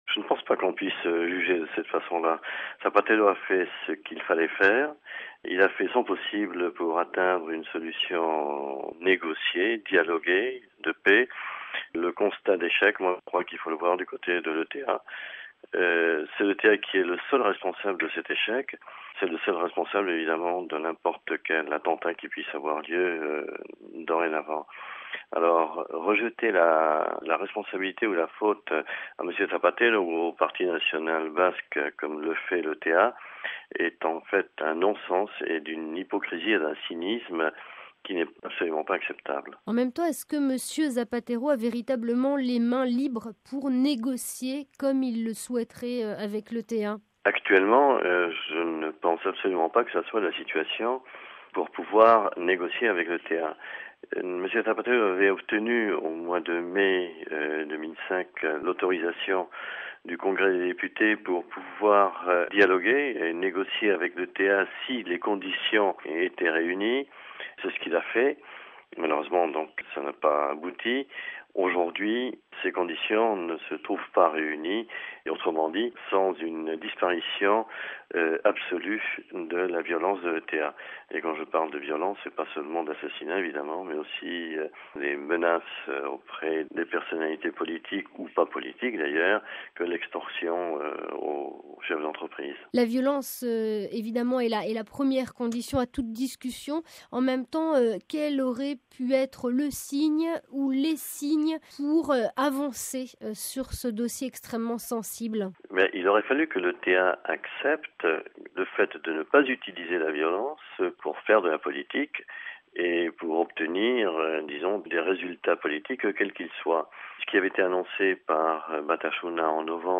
Ecoutez l'analyse de José Maria Munoa, délégué du gouvernement basque pour les relations extérieures RealAudio